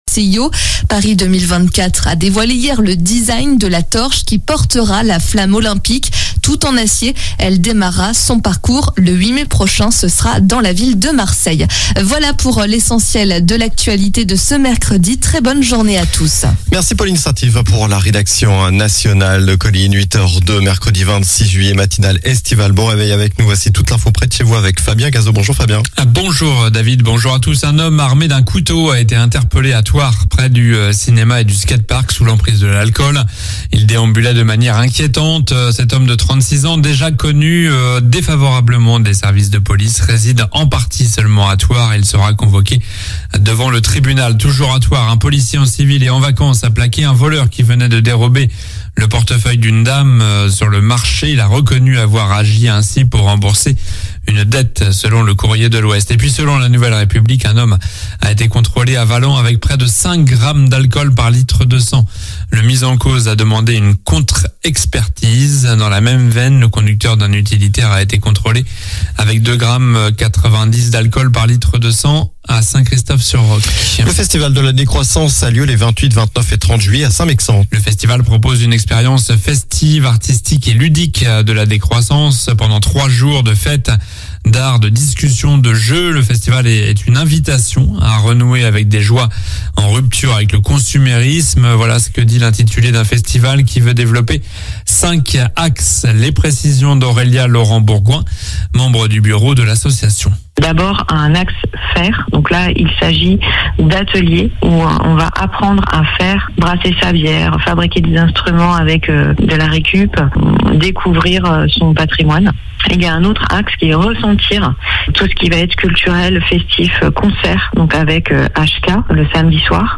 Journal du mercredi 26 juillet (matin)